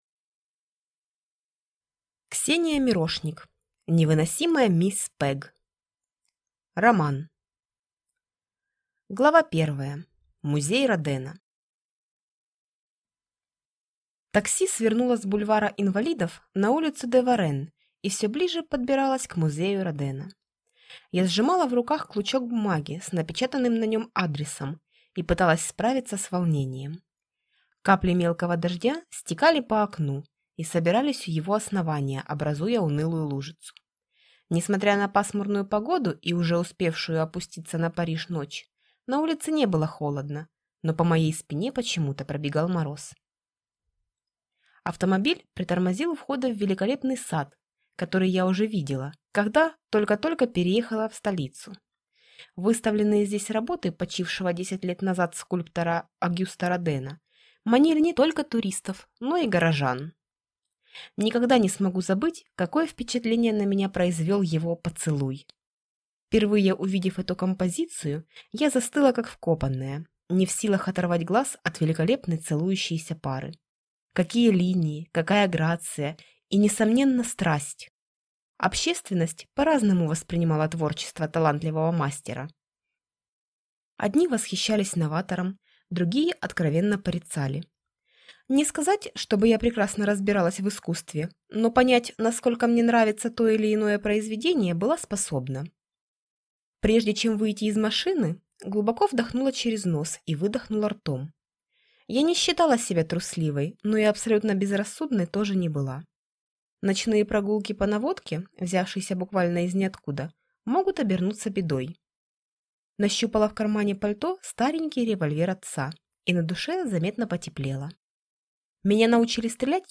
Аудиокнига Невыносимая мисс Пэг | Библиотека аудиокниг